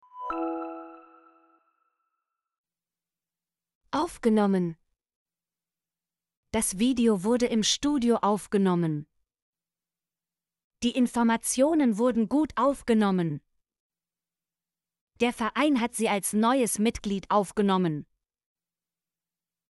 aufgenommen - Example Sentences & Pronunciation, German Frequency List